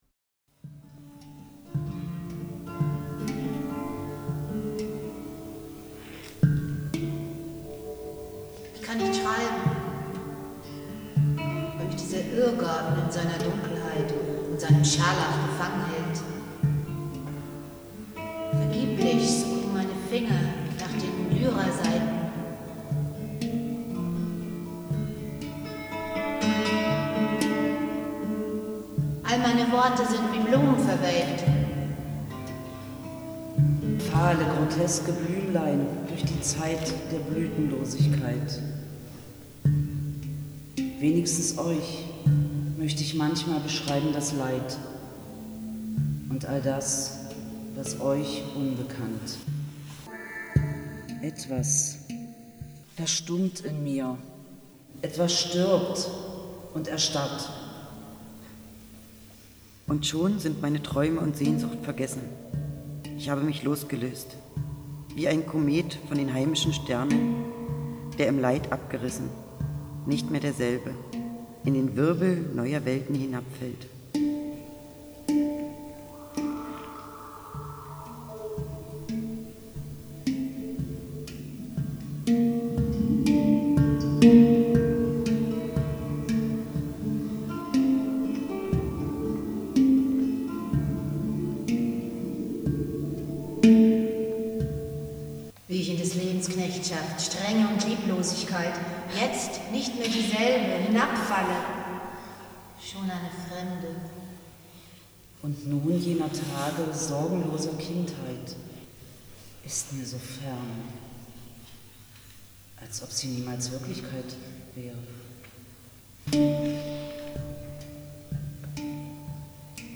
Die musikalische Lesung besteht aus Zitaten und Erinnerungen und erzählt von der Geschichte dieses Schmuggelfundes und von seinen Inhalten.
Eddi, der Hund
ein Vogel, der in der Lichtenburger Schlosskirche rastete und unser Treiben kommentierte.
Gedicht von Zofia Gorska